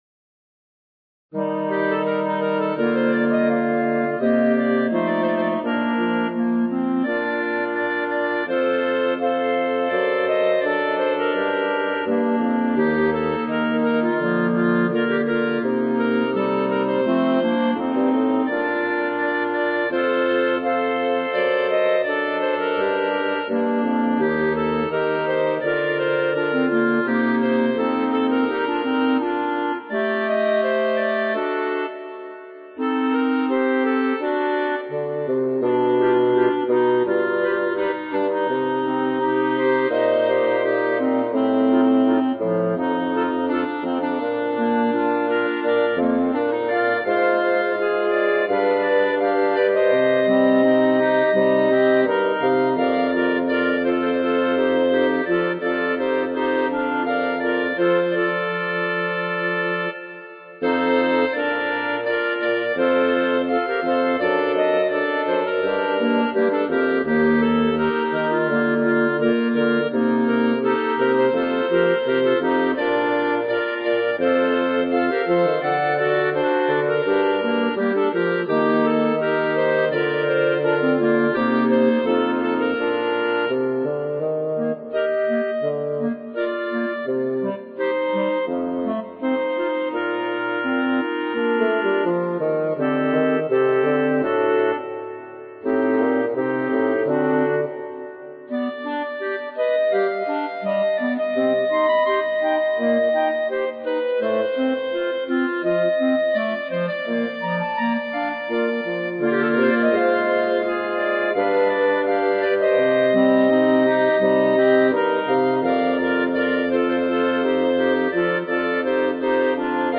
B♭ Clarinet 1 B♭ Clarinet 2 B♭ Clarinet 3 Bass Clarinet
单簧管四重奏
风格： 流行